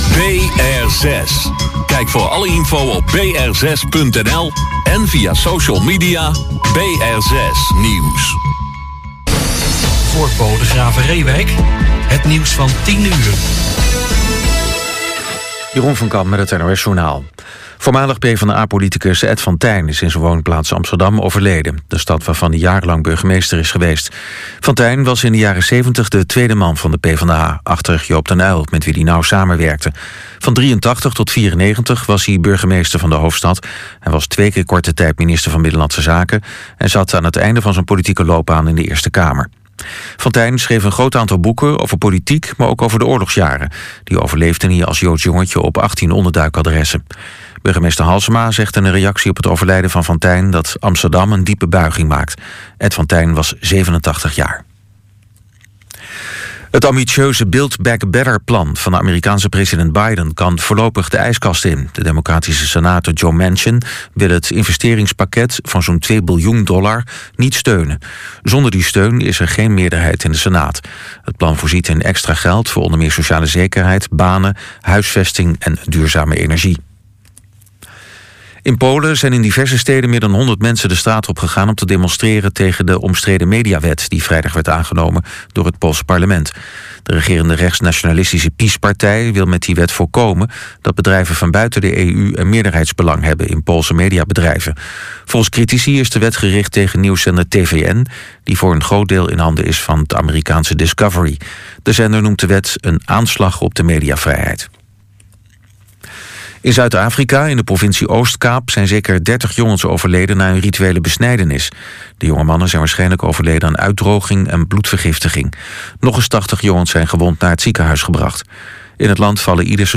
Zang – Akoestisch gitaar
Contrabas
Concertina